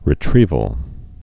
(rĭ-trēvəl)